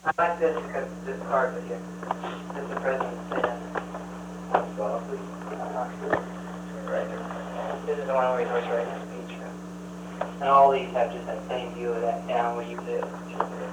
Secret White House Tapes
Conversation No. 241-5
Location: Camp David Hard Wire
Unknown men met.